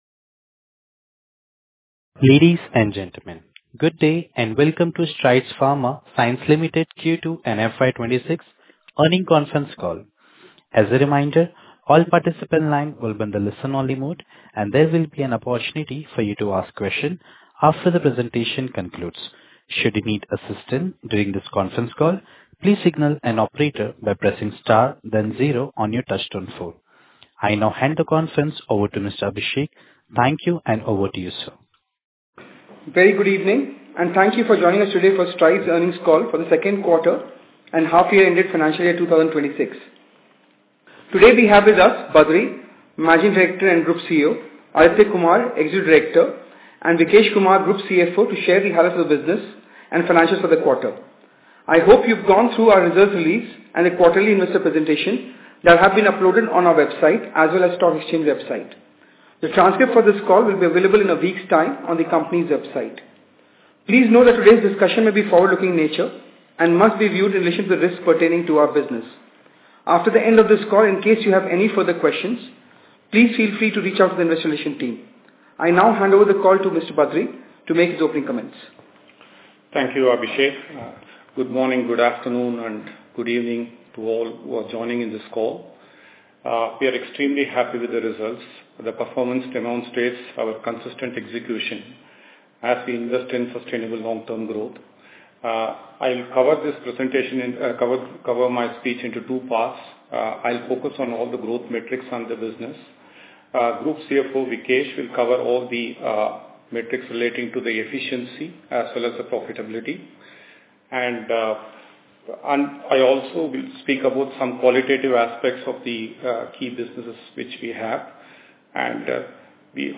Earnings Call Recording